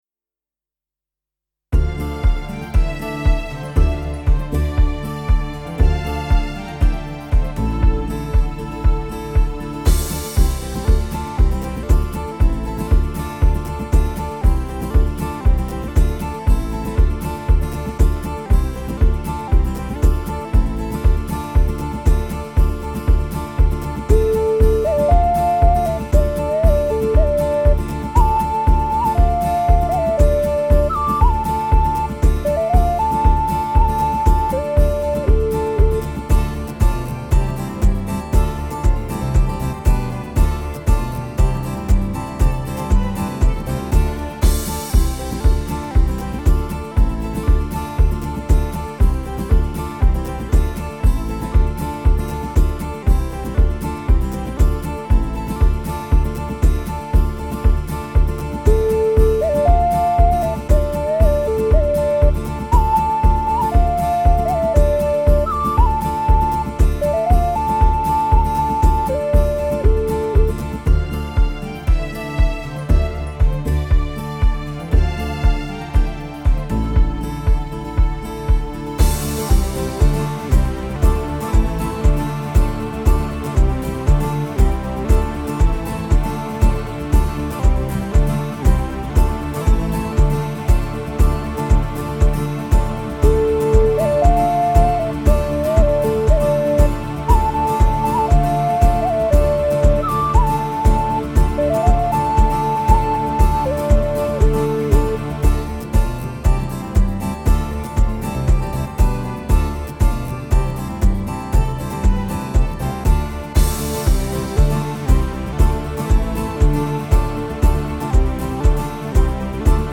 Home > Music > Pop > Bright > Folk > Running